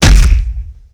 HeavyHit3.wav